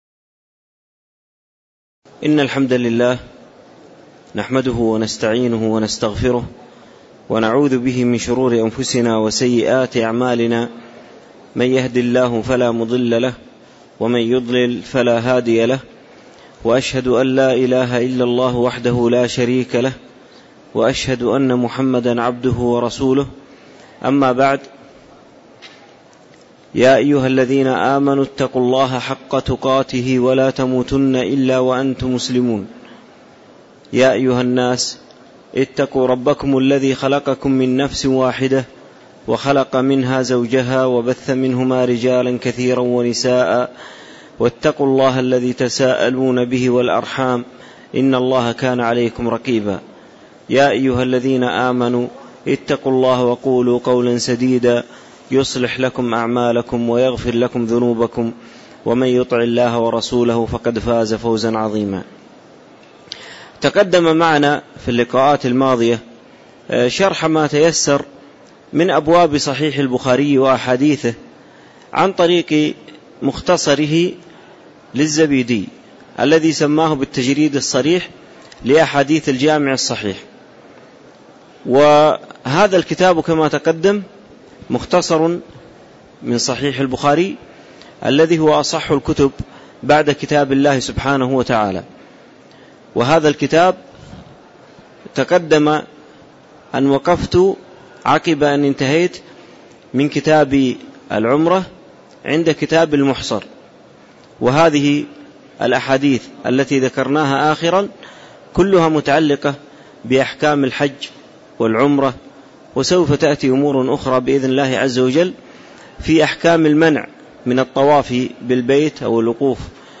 تاريخ النشر ٢١ ذو القعدة ١٤٣٧ هـ المكان: المسجد النبوي الشيخ